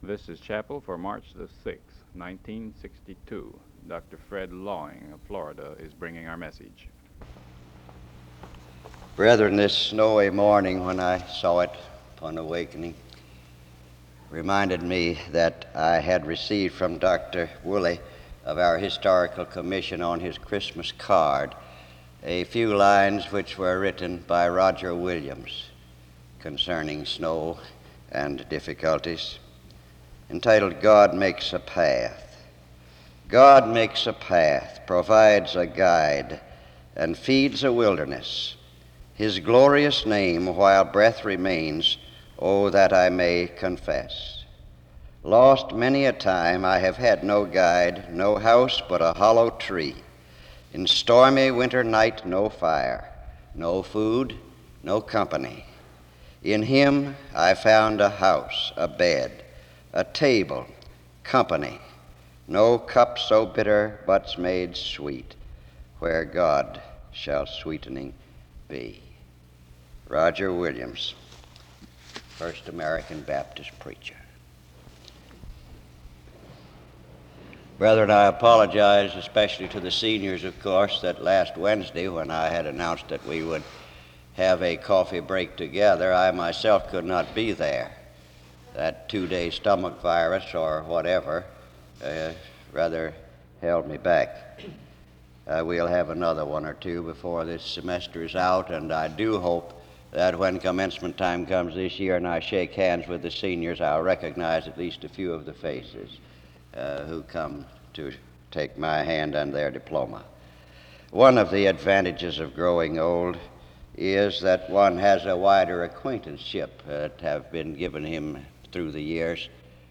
SEBTS Chapel